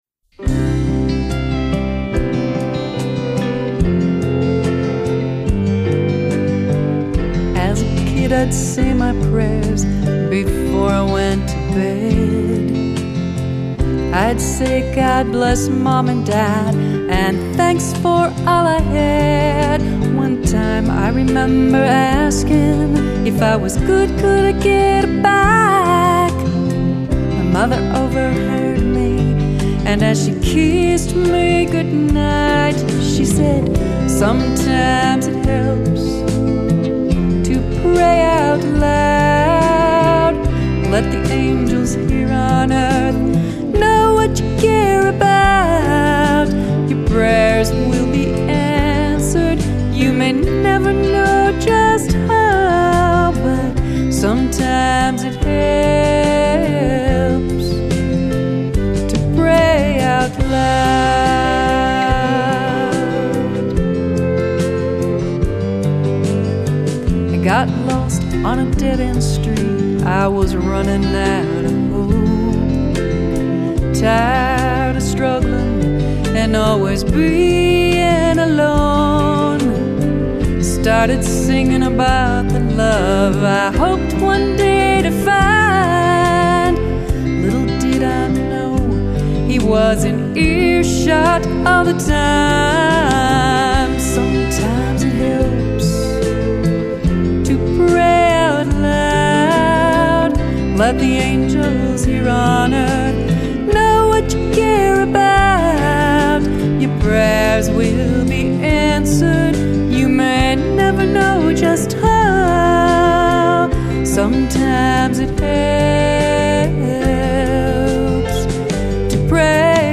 Final CD mix
Drums
Keyboards
MSA pedal steel, bass